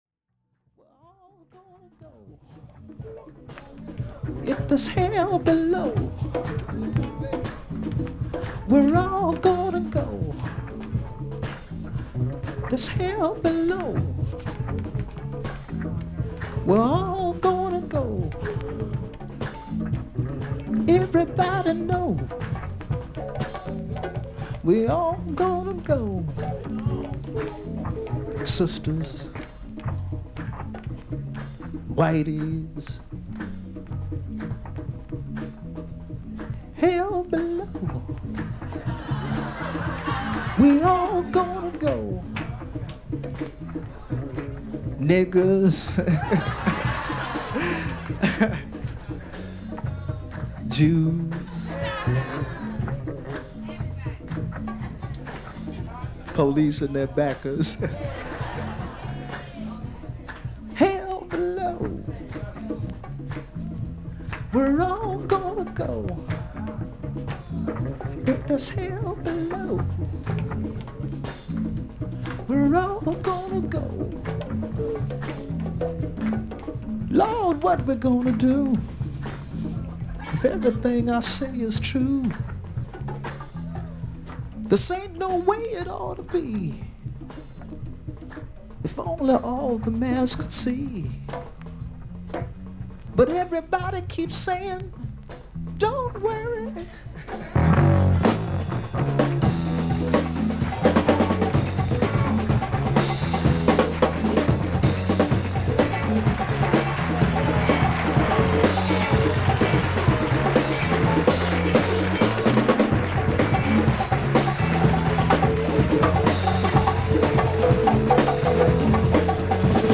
少々軽い周回ノイズあり。
ほかはVG++:少々軽いパチノイズの箇所あり。クリアな音です。
ソウル・シンガー/ソング・ライター/ギタリスト。ソロ2作目のライヴ・アルバム。